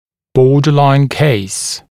[‘bɔːdəlaɪn keɪs][‘бо:дэлайн кейс]пограничный случай